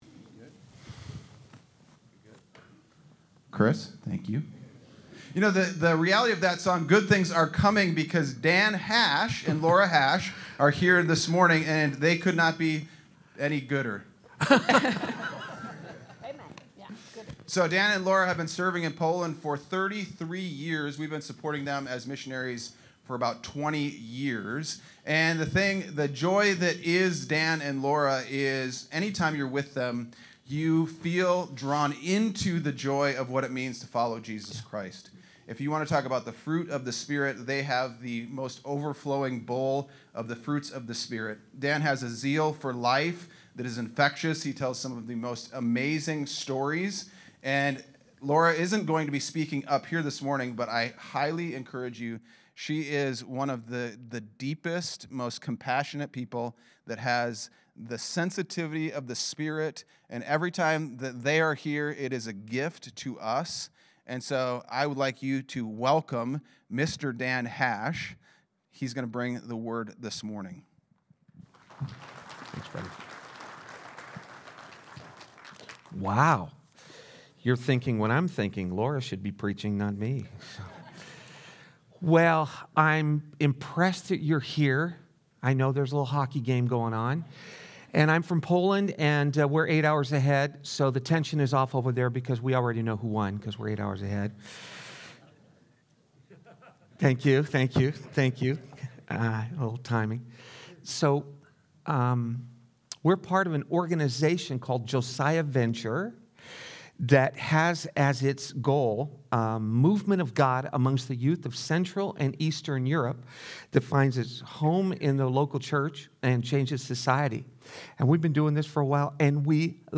Sunday Sermon: 2-22-26